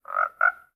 mob / frog / idle1.ogg